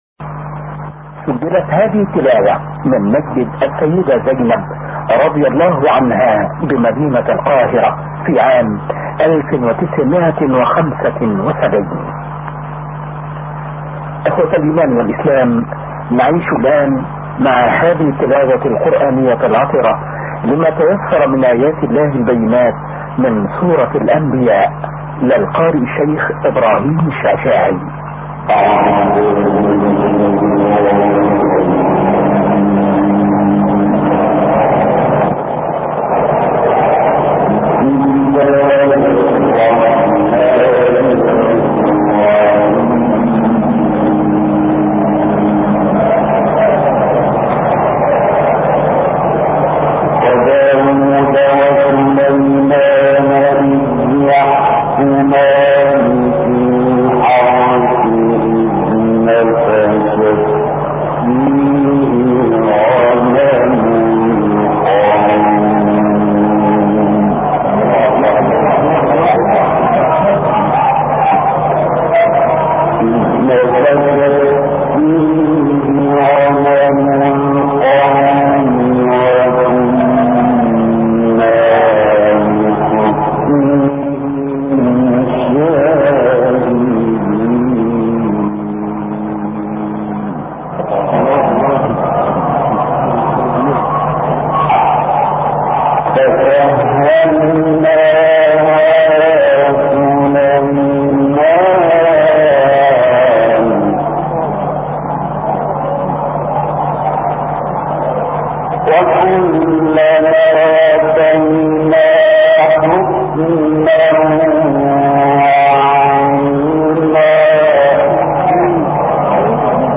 ملف صوتی ما تيسر من سورة الانبياء - 4 بصوت إبراهيم عبدالفتاح الشعشاعي